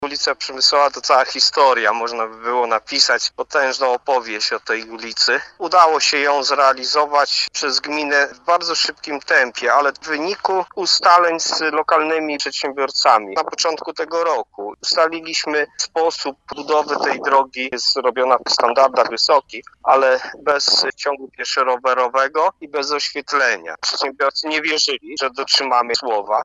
Mówi burmistrz Niska Waldemar Ślusarczyk: